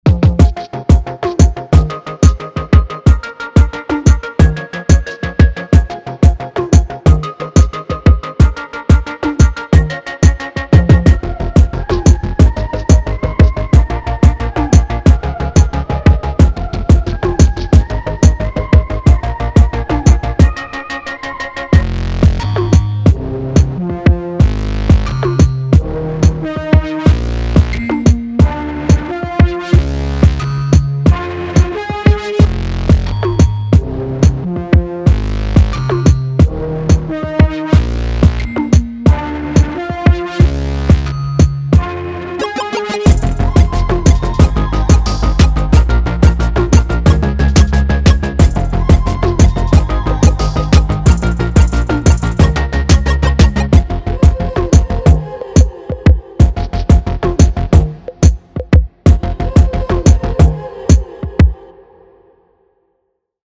BOUNCY ELECTRO
Energetic / Quirky / Weird / Film